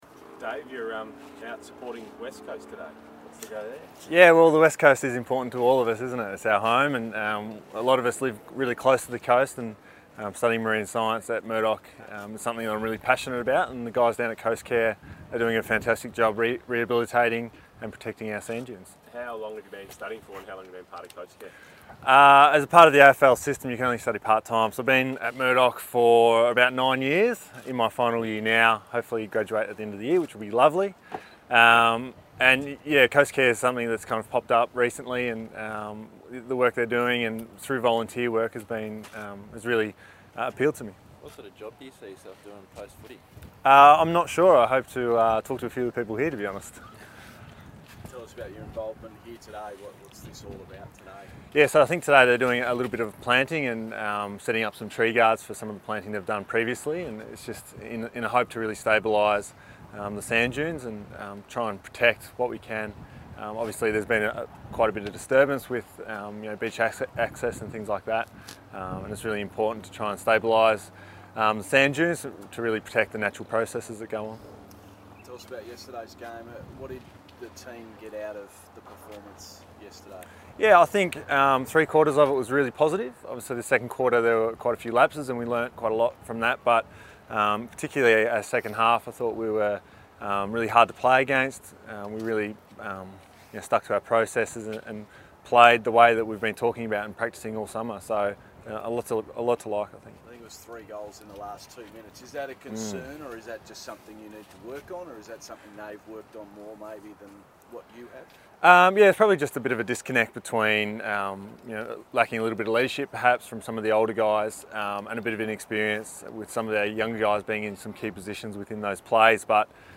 David Mundy media conference - Monday 26 February 2018